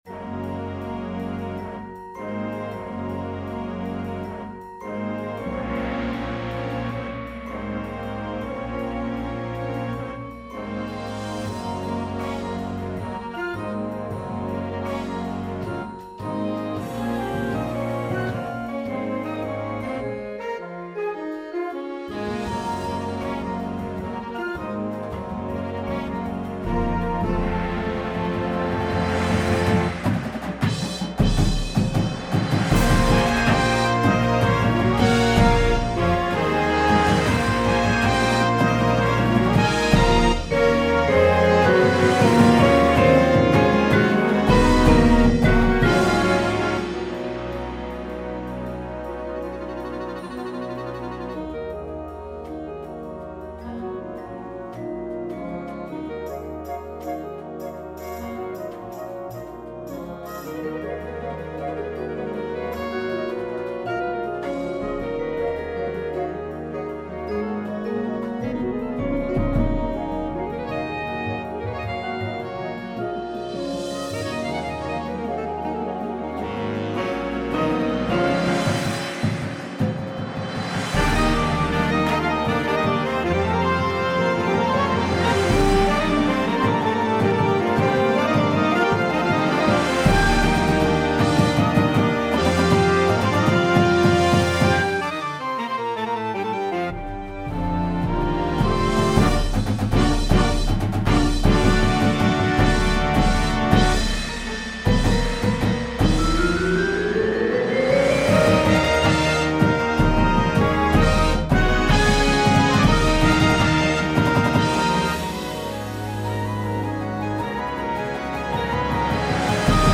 • Flute
• Trumpet 1
• Tuba
• Snare Drum
• Bass Drums